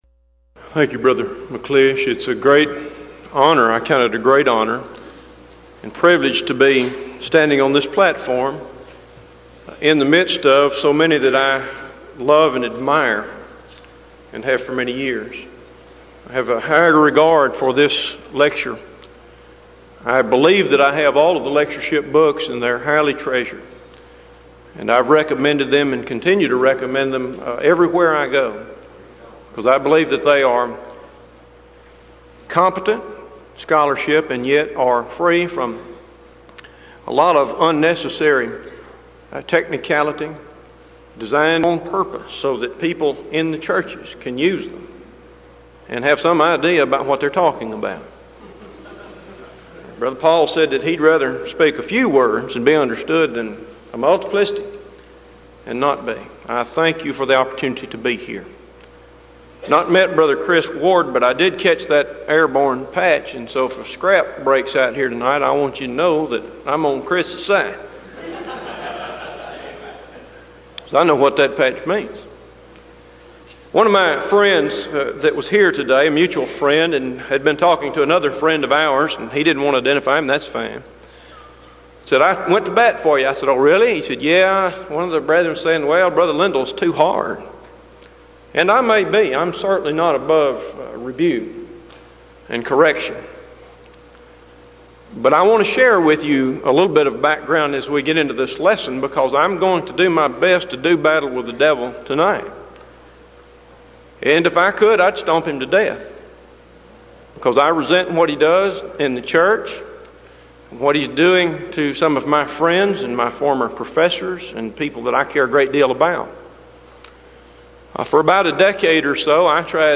Event: 1994 Denton Lectures
lecture